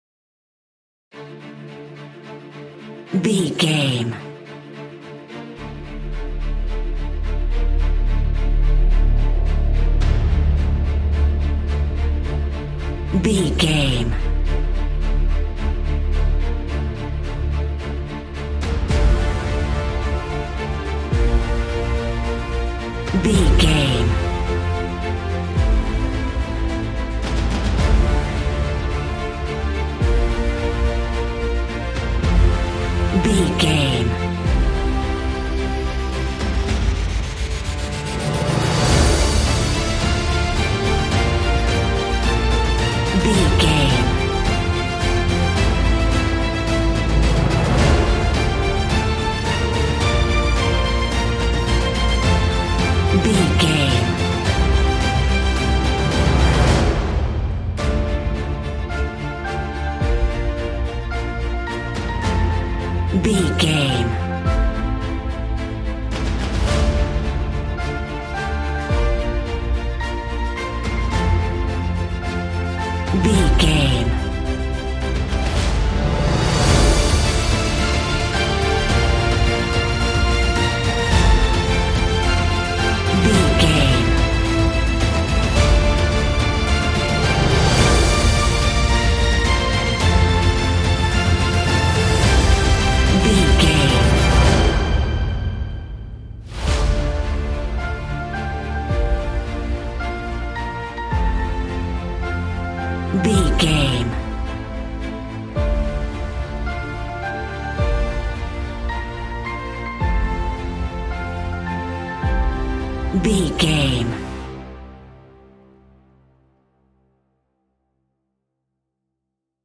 Epic / Action
Fast paced
In-crescendo
Uplifting
Ionian/Major
orchestra